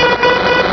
Cri de Démanta dans Pokémon Rubis et Saphir.